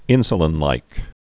(ĭnsə-lĭn-līk)